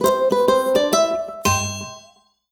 SC_Positive_Stinger_01.wav